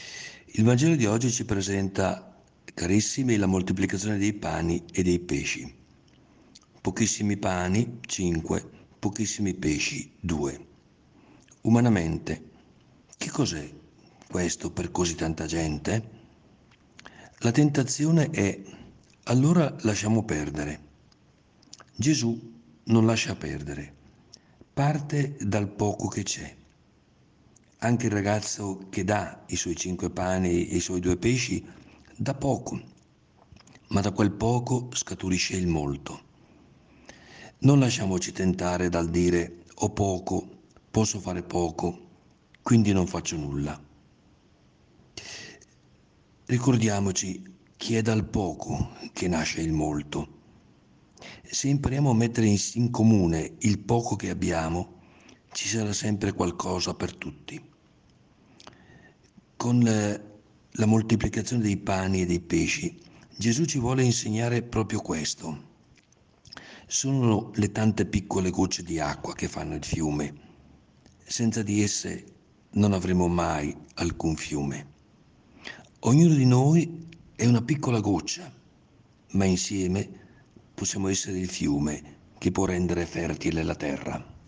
Il Vescovo commenterà la Parola di Dio per trarne ispirazione per la giornata.